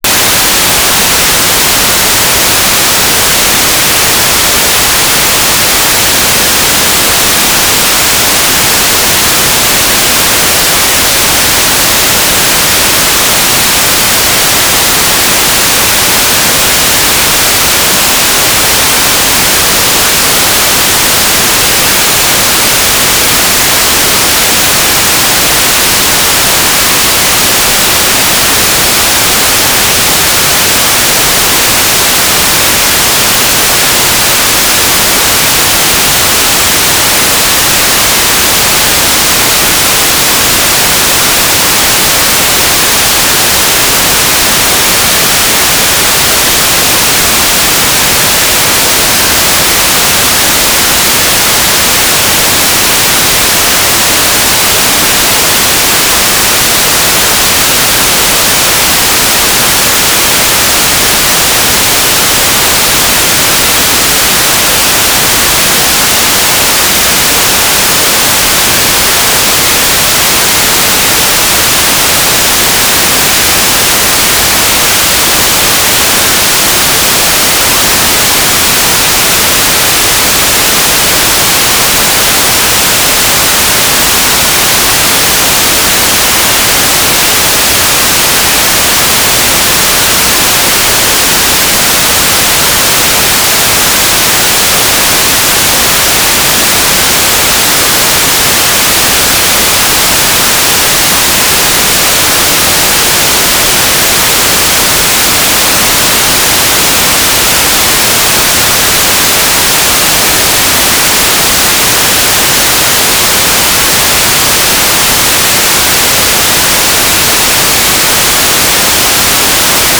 "waterfall_status": "without-signal",
"transmitter_description": "Main telemetry transmitter",
"transmitter_mode": "GMSK USP",